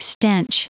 Prononciation du mot stench en anglais (fichier audio)
Prononciation du mot : stench